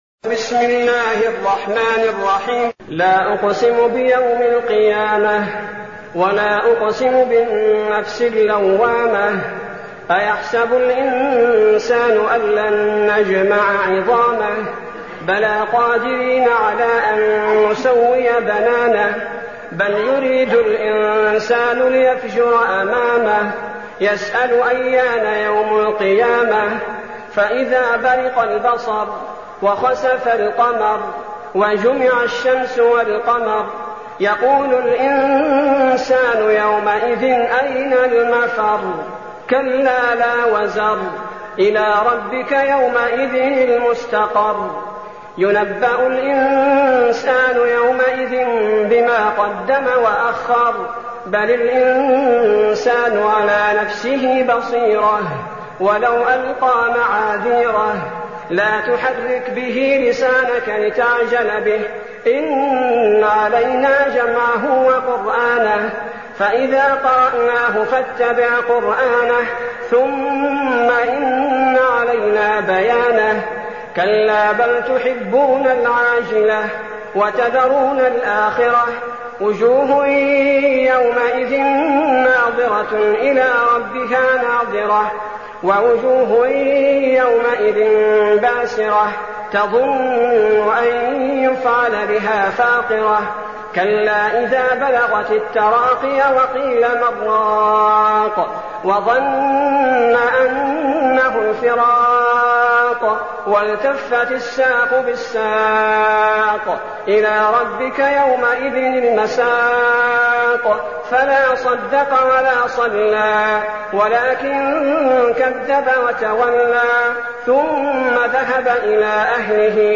المكان: المسجد النبوي الشيخ: فضيلة الشيخ عبدالباري الثبيتي فضيلة الشيخ عبدالباري الثبيتي القيامة